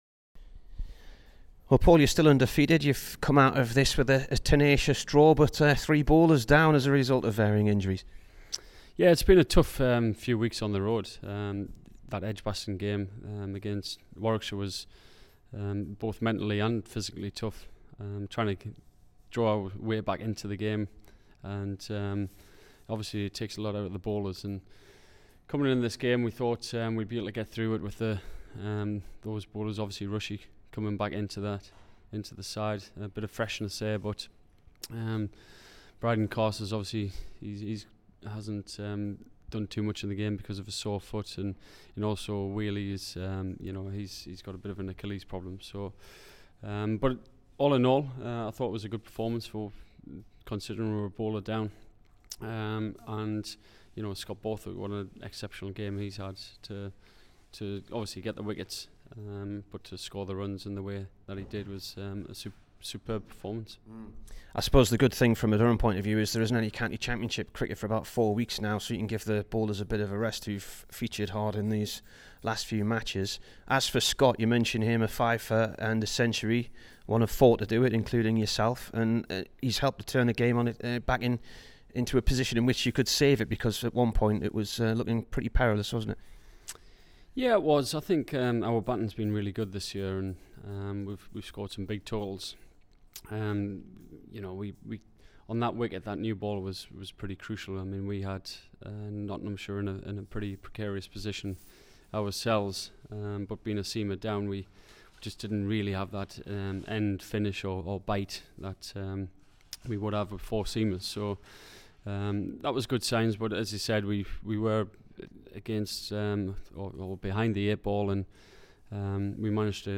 Here's the Durham skipper Paul Collingwood after the draw at Notts.